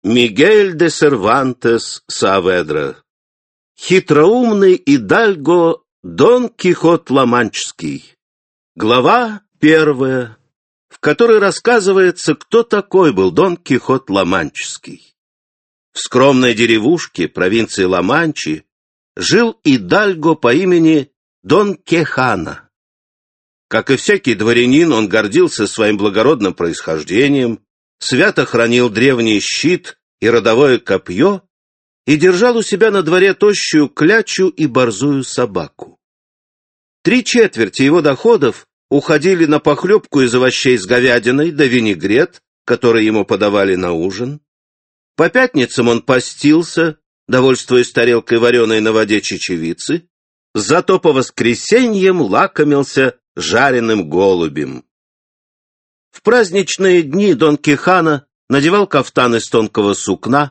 Аудиокнига Благородный идальго Дон-Кихот Ламанчский | Библиотека аудиокниг